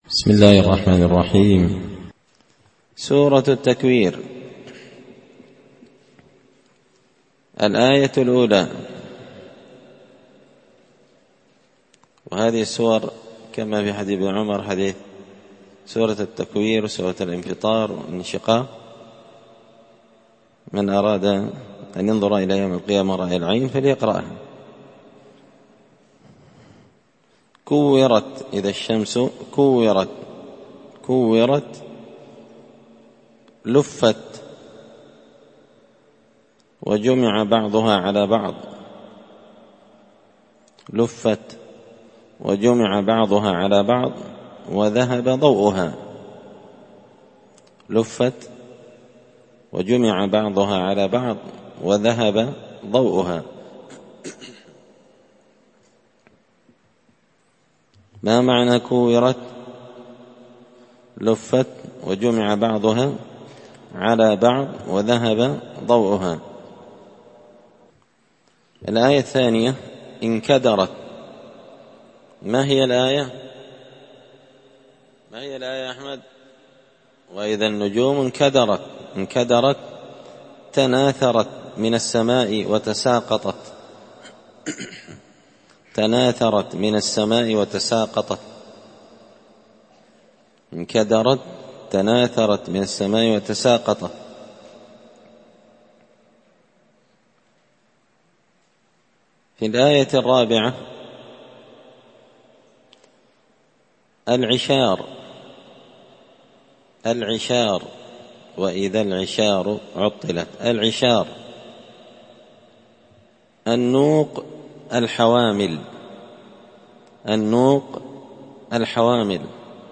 السبت 26 شعبان 1444 هــــ | الدروس، دروس القران وعلومة، زبدة الأقوال في غريب كلام المتعال | شارك بتعليقك | 13 المشاهدات